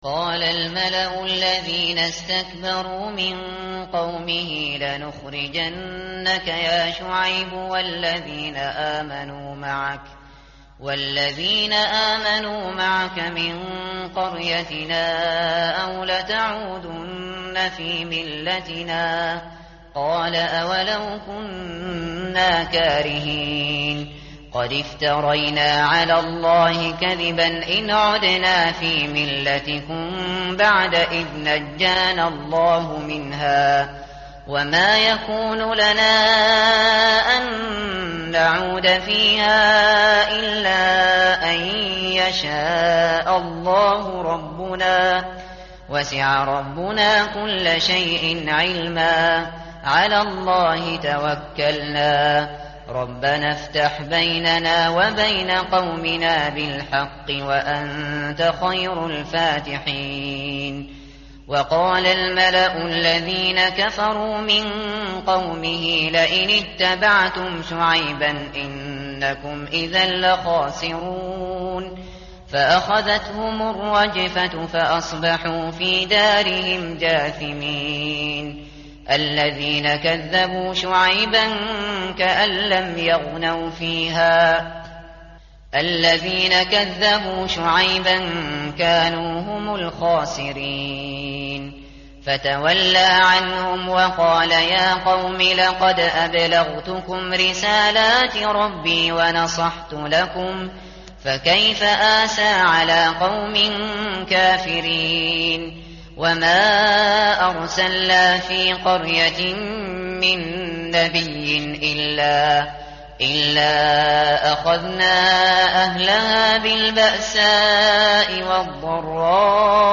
tartil_shateri_page_162.mp3